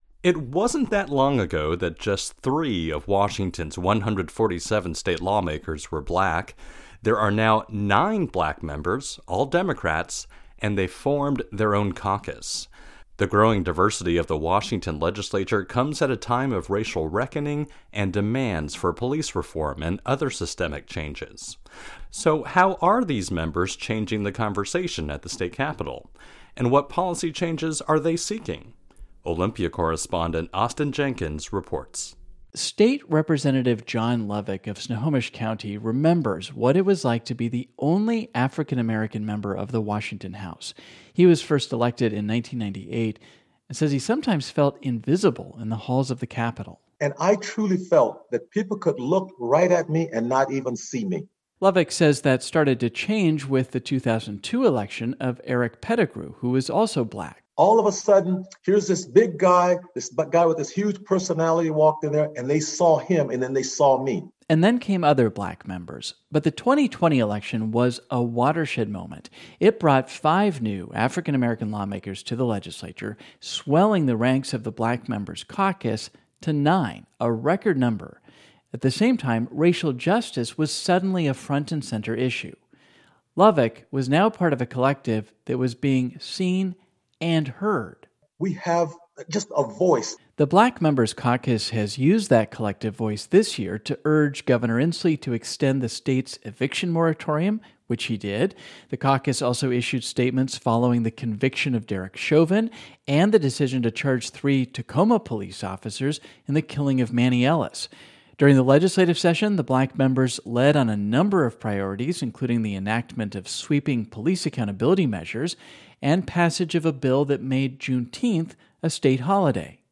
Over the past month-and-a-half the public radio Northwest News Network has engaged the members of the Black Members Caucus in conversation.